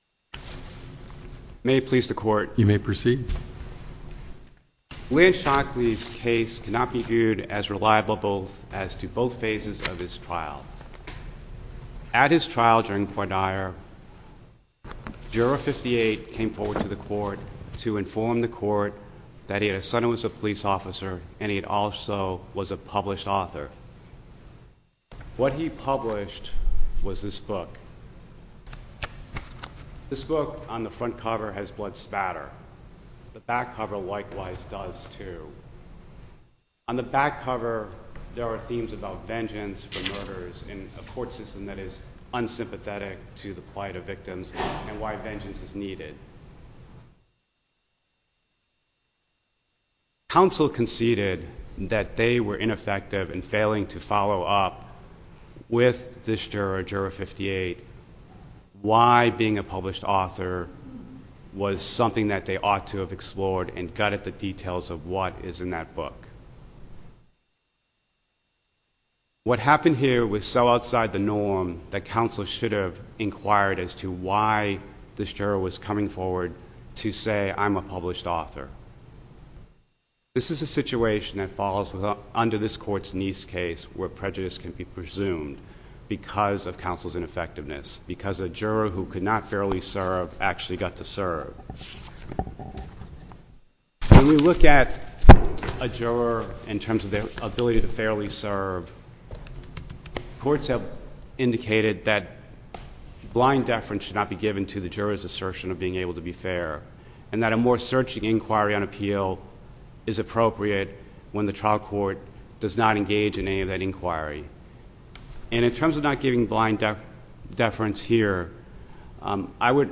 link to MP3 audio file of oral arguments in SC97018